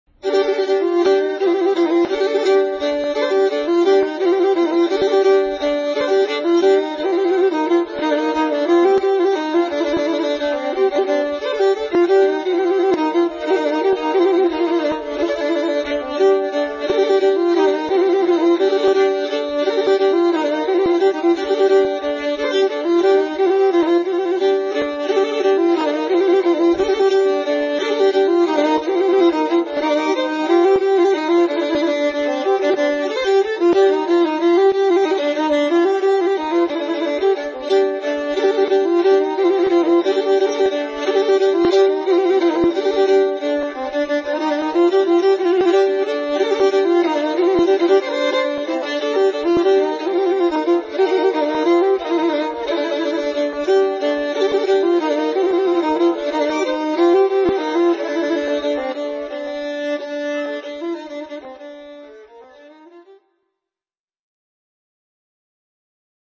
Single Omal. Omal means smooth